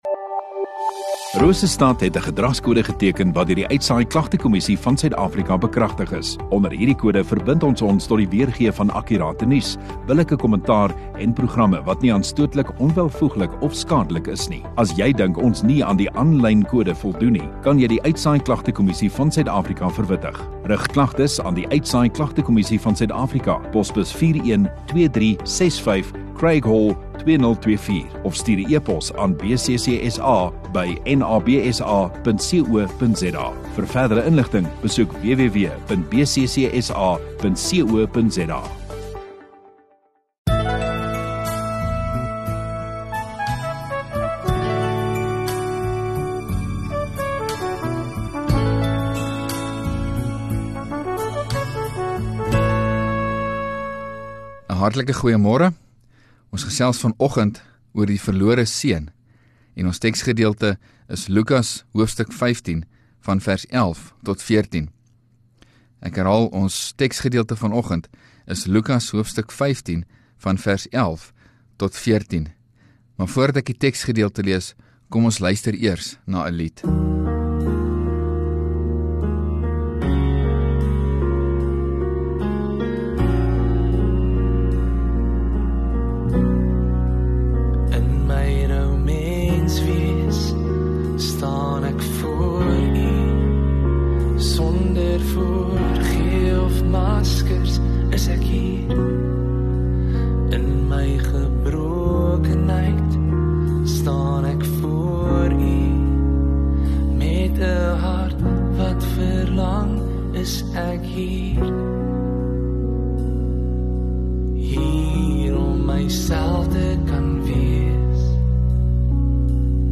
14 Sep Sondagoggend Erediens